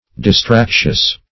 Distractious \Dis*trac"tious\
distractious.mp3